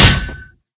minecraft / sounds / mob / zombie / metal1.ogg
metal1.ogg